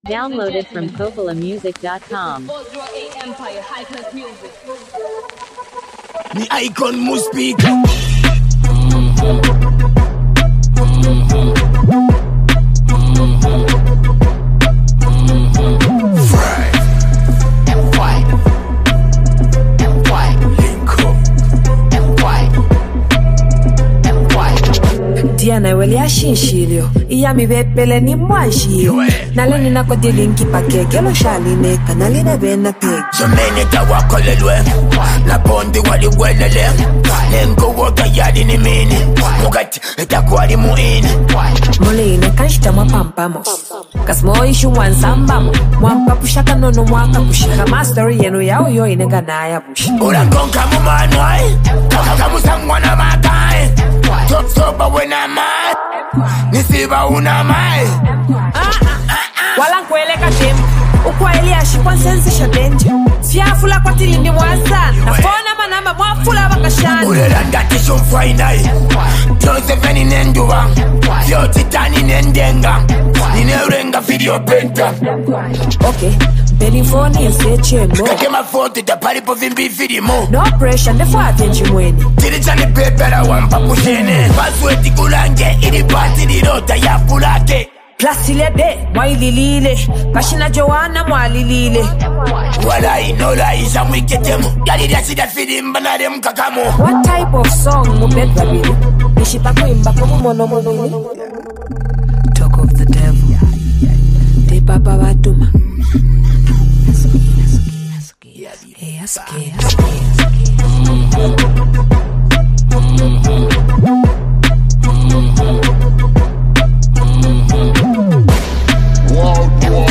giving the song a softer, more romantic balance.
a blend of love, reality, and Zambian musical flavor